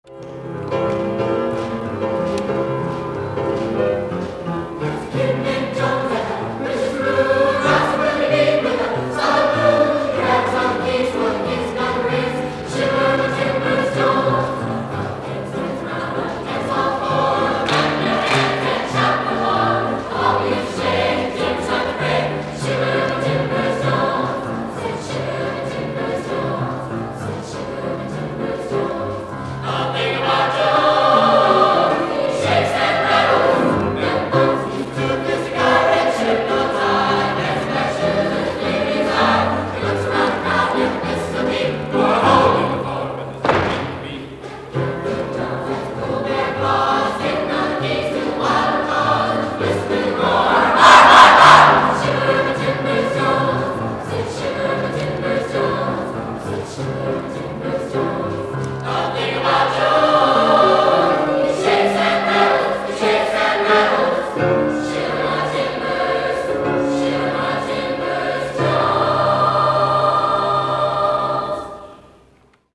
Pop Concert
Clay Jr. High Gym
(partial concert only)